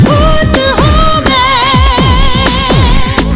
File Type : Horror ringtones